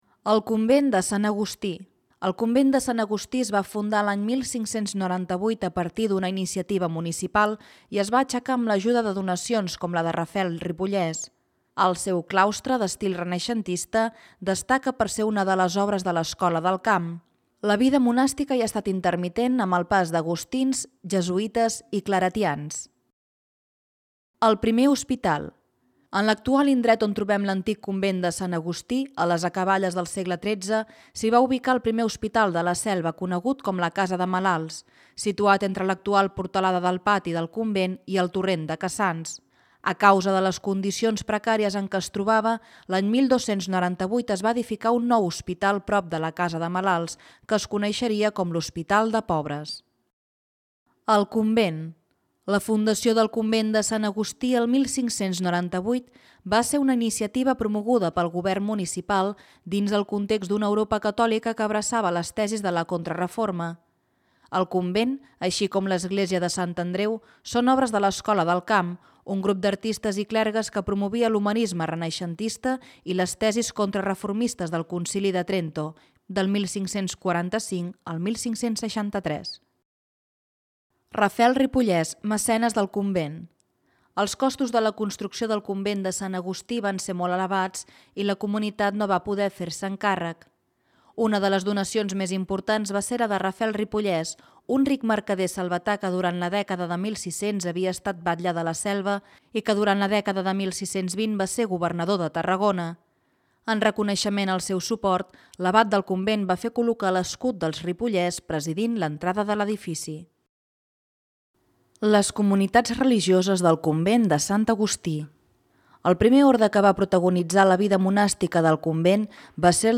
Audio guia